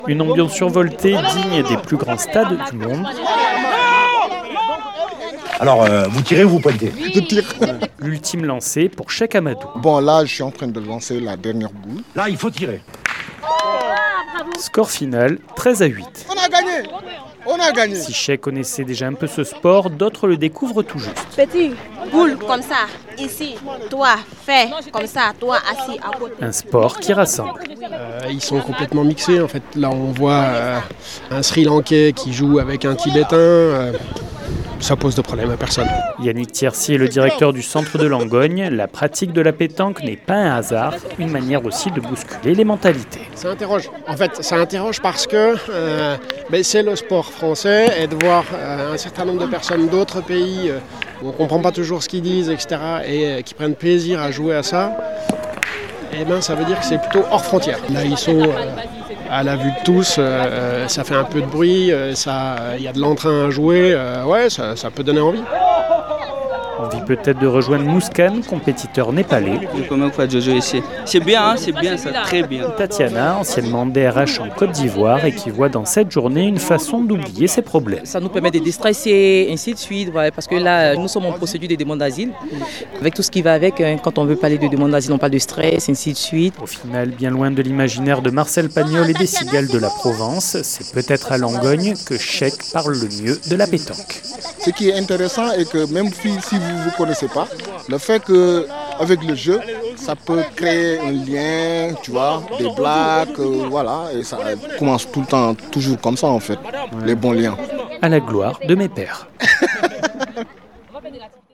Dans le cadre de la Semaine de l’intégration, 48FM s’est rendu à l’une de ces parties endiablées.
Reportage